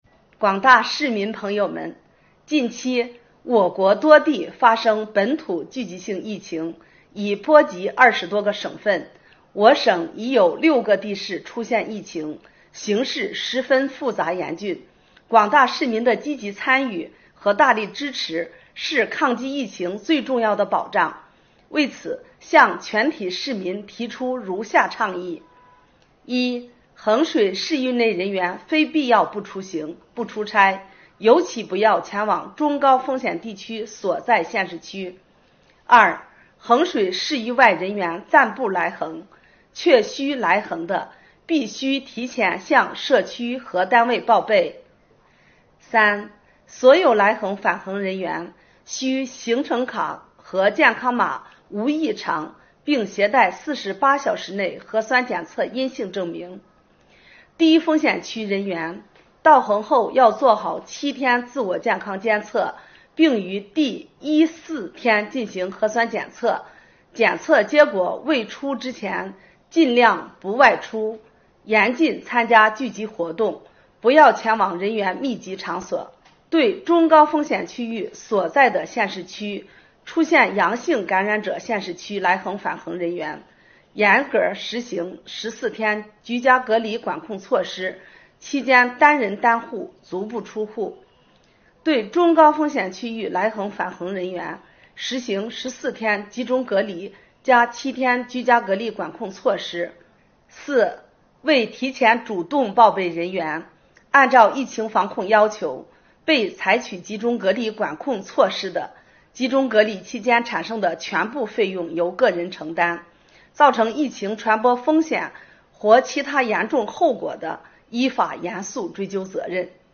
衡水市人民政府副市长崔海霞向广大市民发出倡议